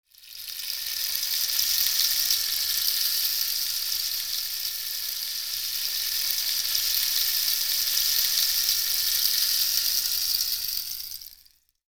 percussion 21.wav